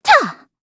Xerah-Vox_Attack2_kr.wav